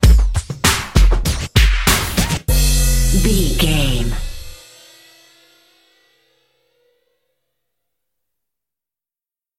Ionian/Major
drum machine
synthesiser
hip hop
Funk
neo soul
acid jazz
energetic
bouncy
funky
hard hitting